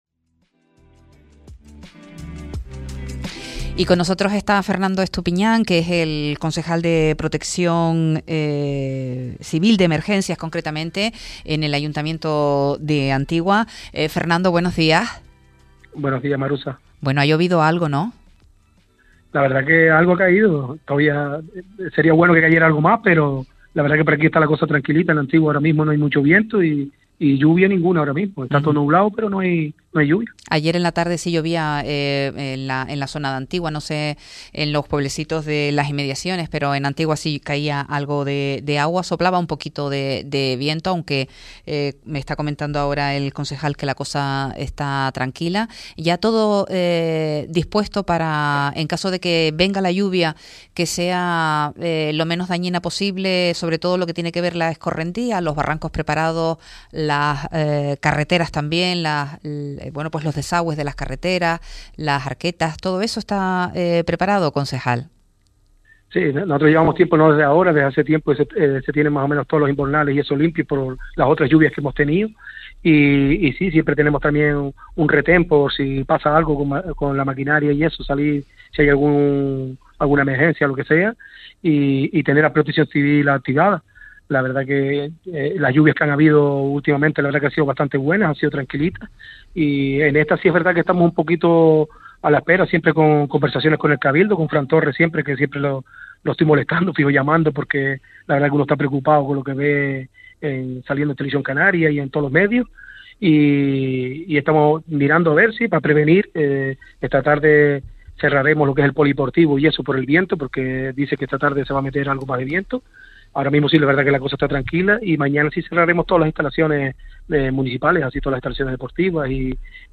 Entrevistas
Protección Civil en Antigua, un retén de trabajadores municipales y distinta maquinaria, ya están coordinados en Antigua para abordar cualquier situación que se pueda generar en el municipio por el temporal que se prevé que deje en Fuerteventura la borrasca Therese .Así lo trasladó en Radio Sintonía, el Concejal de Emergencias, Fernando Estupiñan, quien también resaltó la coordinación con el departamento de Seguridad y Emergencias del Cabildo de Fuerteventura.